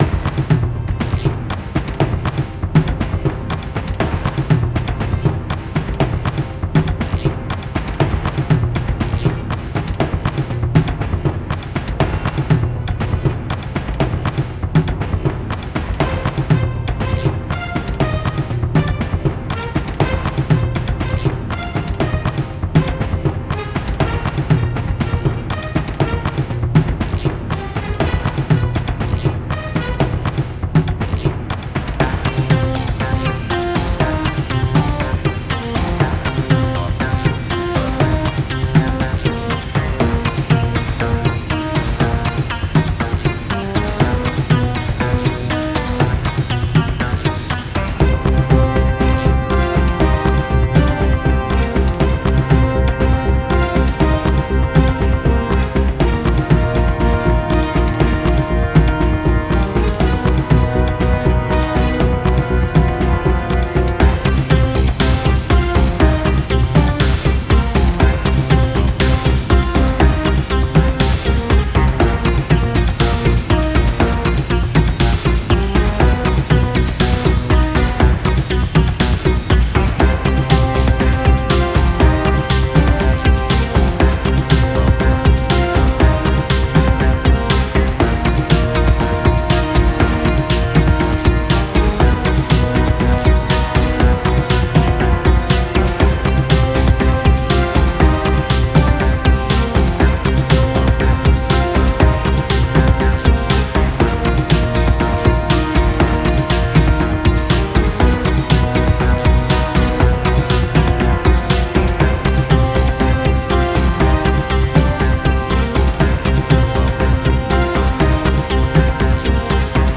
Cinematic underscore